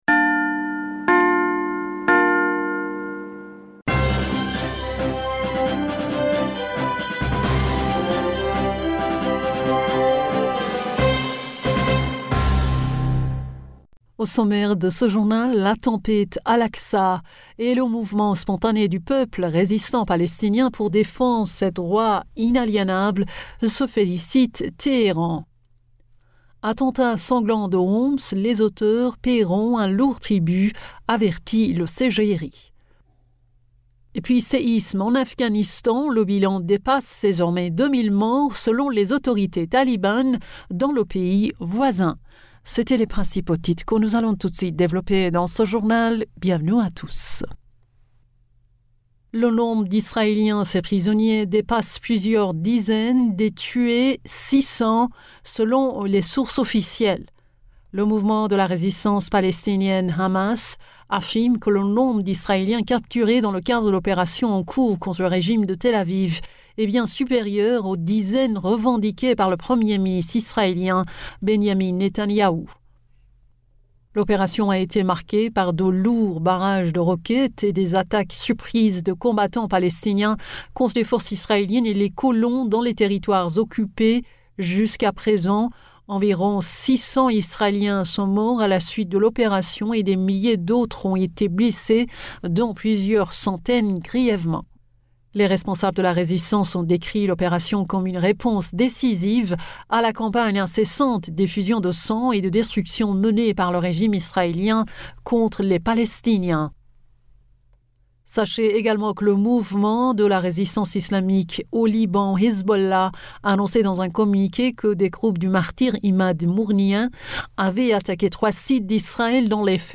Bulletin d'information du 08 Octobre 2023